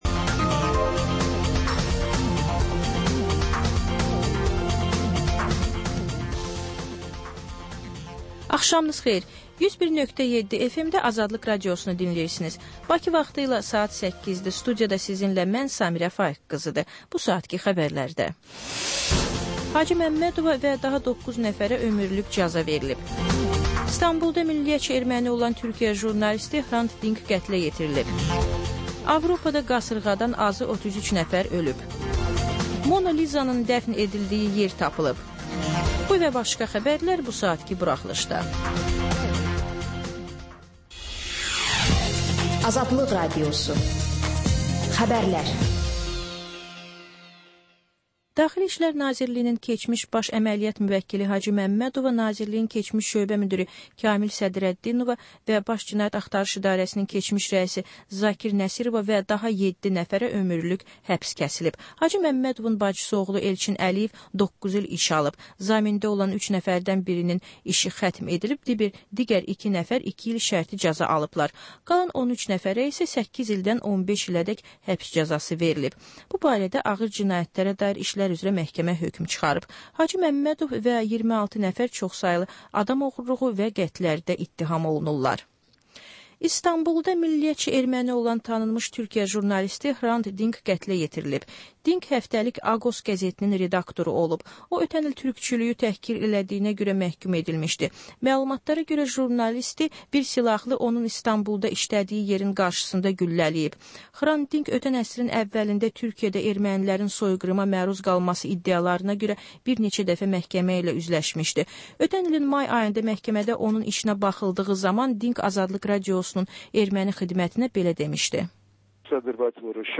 Xəbər, reportaj, müsahibə. Sonra: Günün Söhbəti: Aktual mövzu barədə canlı dəyirmi masa söhbəti.